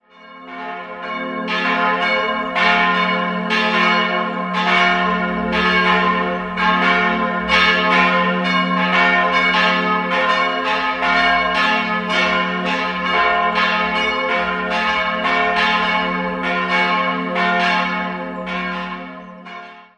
Bemerkenswert im gefälligen Innenraum des Neubaus sind die großen, farbenfrohen Glasfenster. 3-stimmiges F-Moll-Geläute: f'-as'-c'' Die beiden großen Glocken wurden zwischen 1946 und 1950 von Hamm gegossen, die kleine ist historisch und stammt aus dem 15. Jahrhundert.